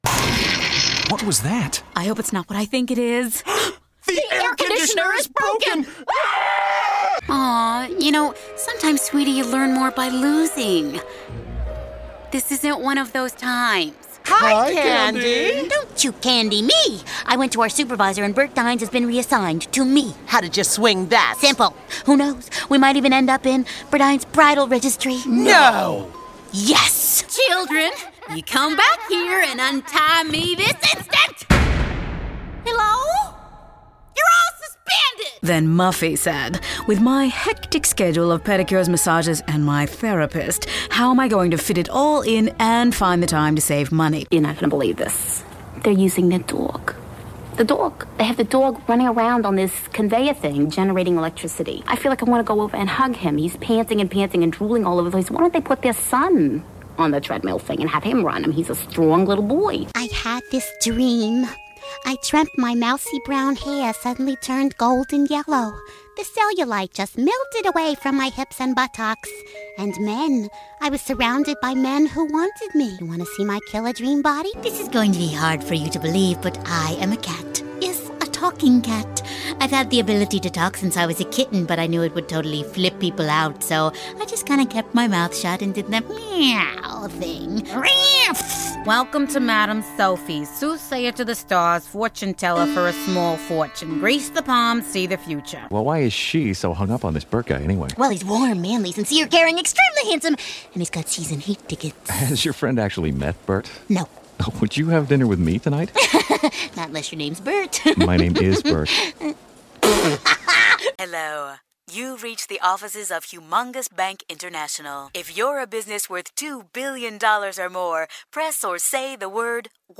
COMEDY-CHARCATER COMMERCIAL